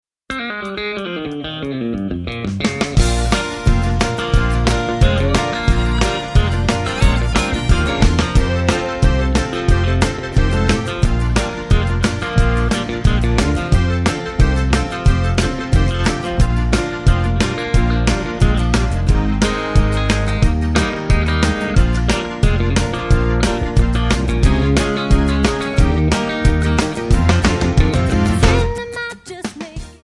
G/A
MPEG 1 Layer 3 (Stereo)
Backing track Karaoke
Country, 1990s